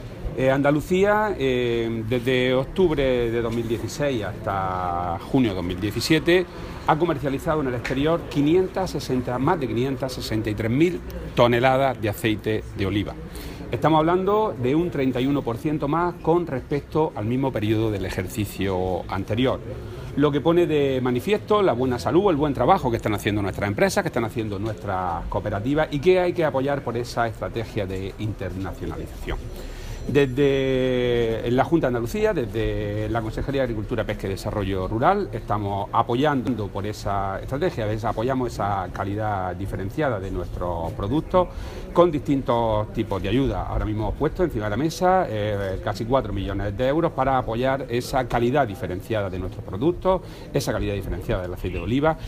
Declaraciones de Rodrigo Sánchez sobre exportaciones de aceite de oliva